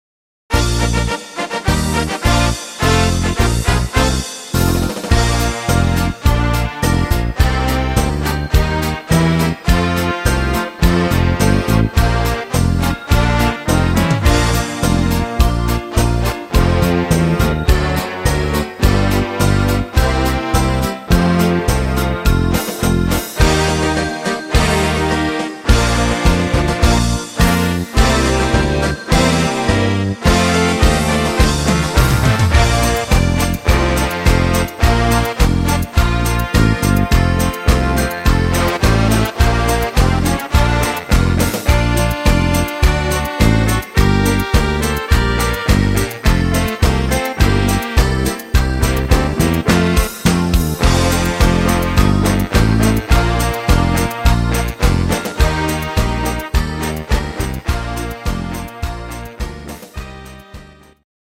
Kölsch Karnevalslied